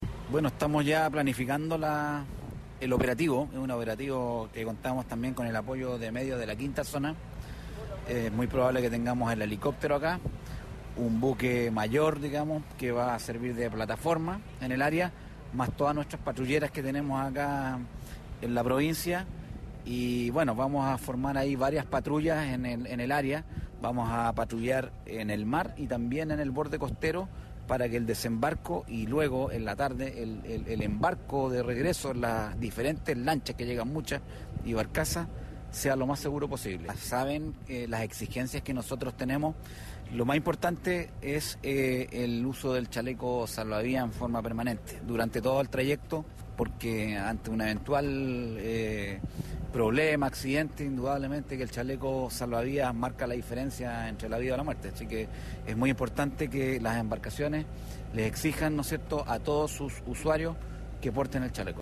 El gobernador marítimo de Castro, Javier Mardones, expresó que se contará en la oportunidad con el apoyo de la logística de la quinta zona naval de Puerto Montt.